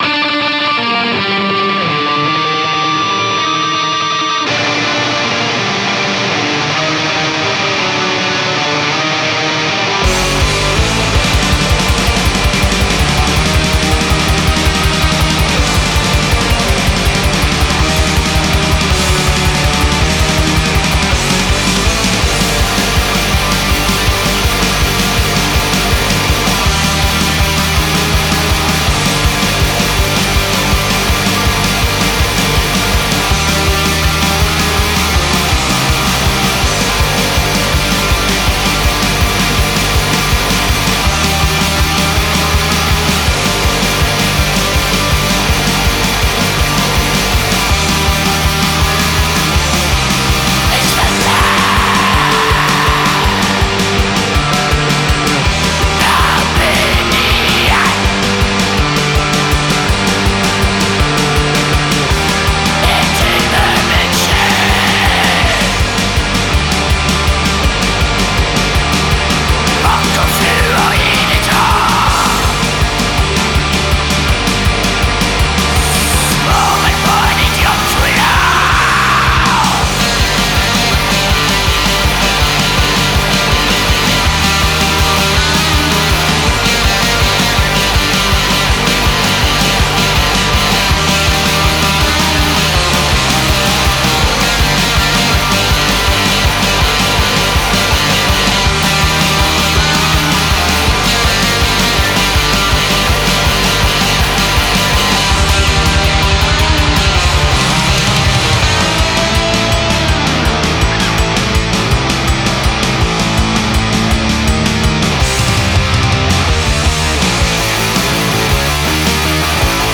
genre black metal